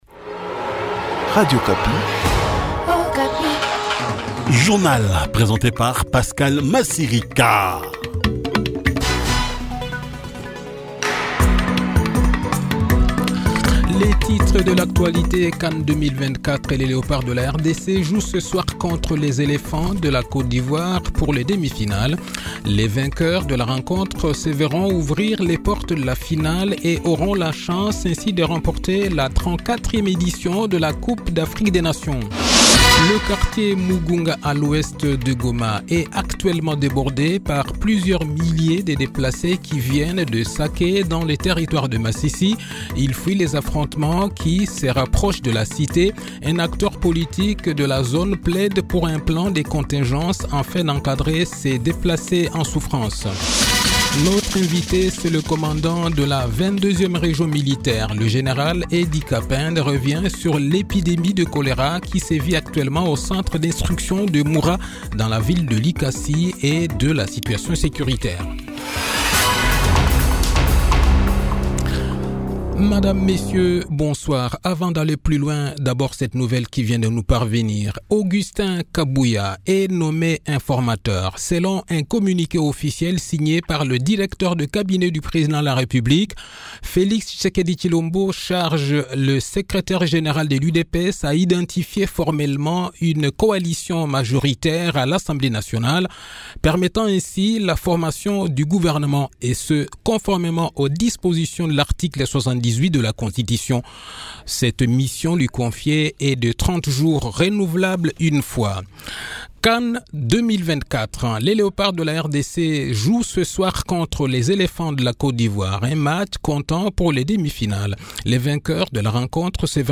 Journal Soir
Le journal de 18 h, 7 fevrier 2024